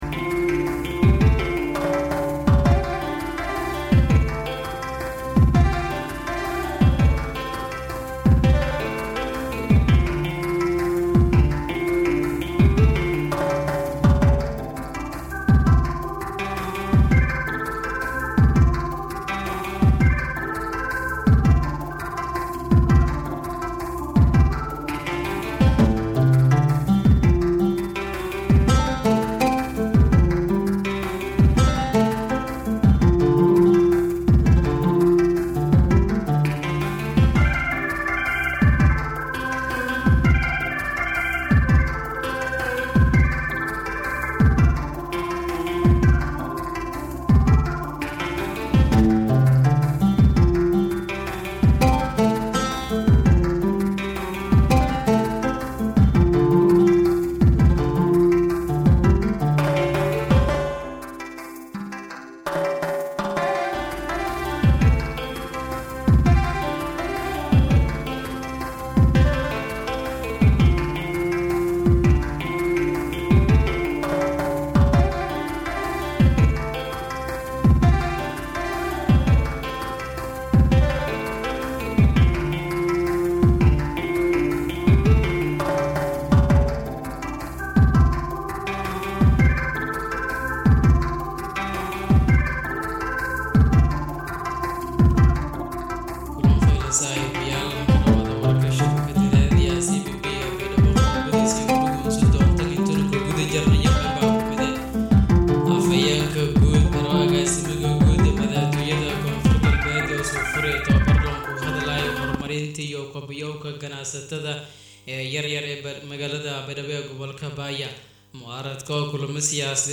{DHAGEYSO} Warka Duhurnimo ee Warbaahinta Radio Codka Baay Iyo Bakool {24.5.2025}